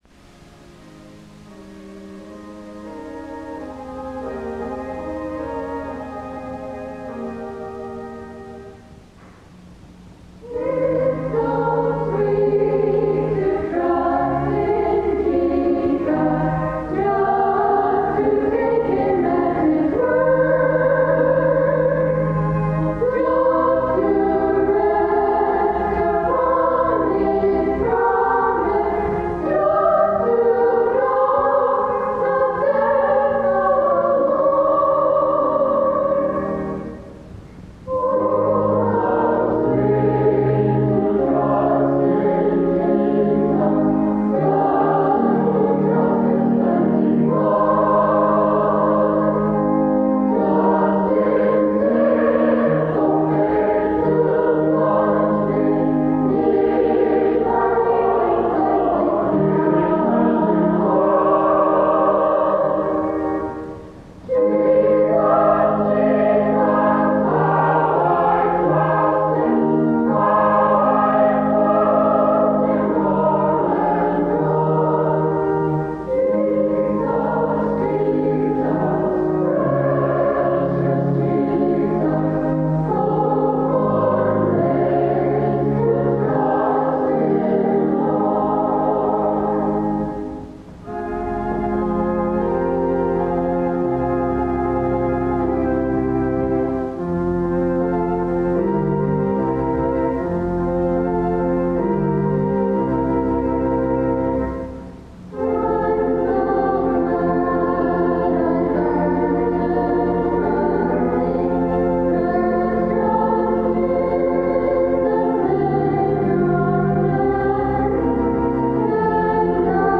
Audio Quality: Poor